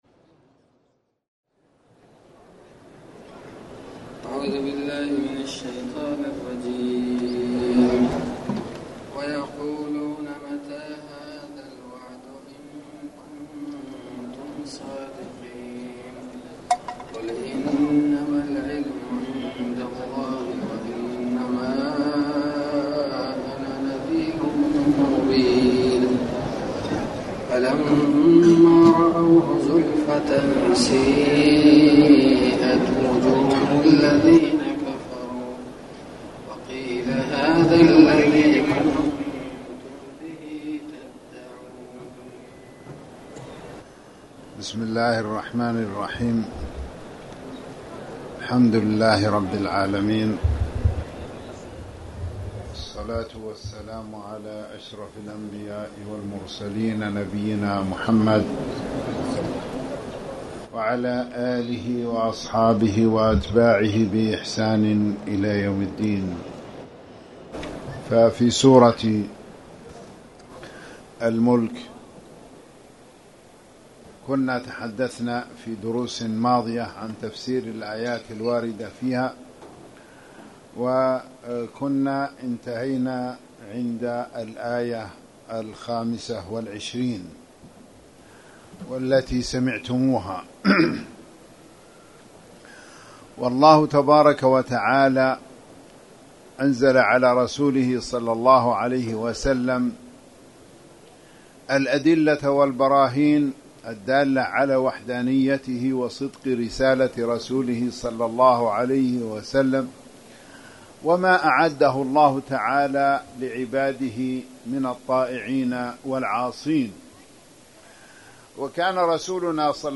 تاريخ النشر ٢٤ صفر ١٤٣٩ هـ المكان: المسجد الحرام الشيخ